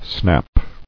[snap]